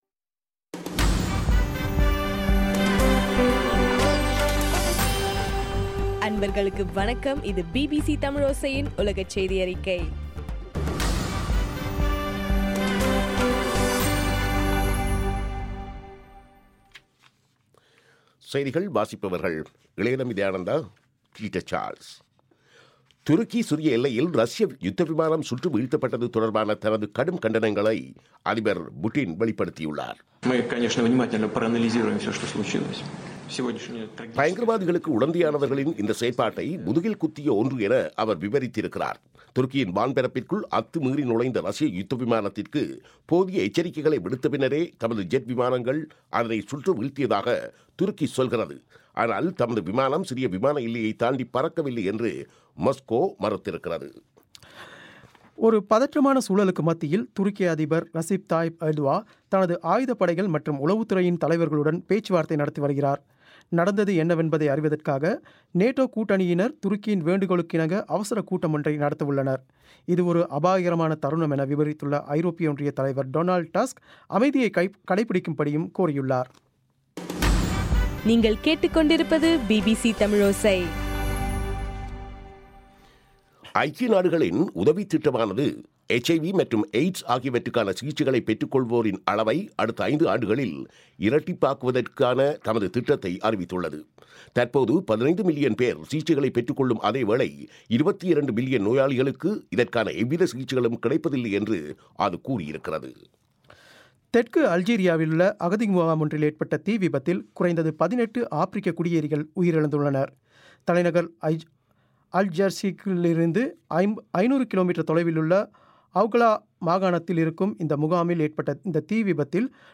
இன்றைய (நவம்பர் 24) பிபிசி தமிழோசை செய்தியறிக்கை